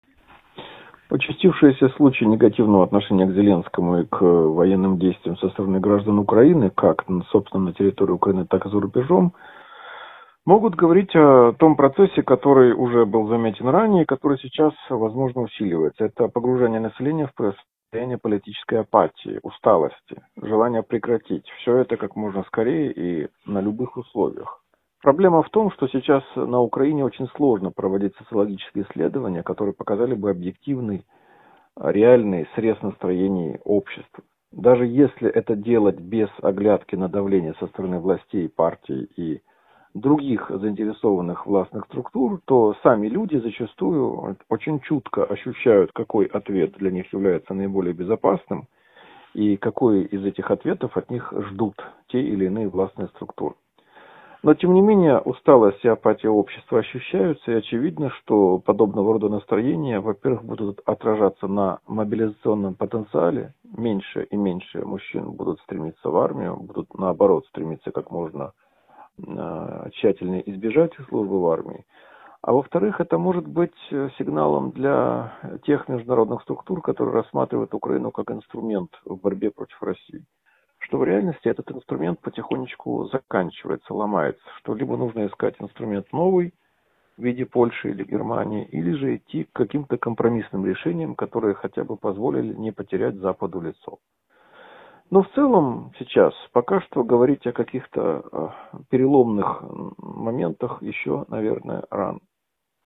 ГЛАВНАЯ > Актуальное интервью
Член президентского Совета по межнациональным отношениям Богдан Безпалько в интервью журналу «Международная жизнь» рассказал о ситуации на Украине: